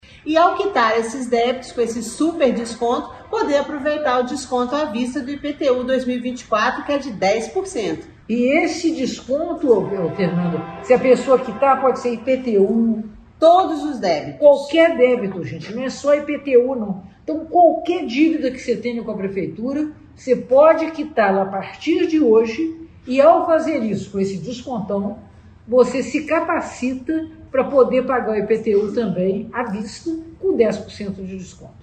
A secretária de Fazenda, Fernanda Finotti e a prefeita Margarida Salomão explicam como funciona o edital.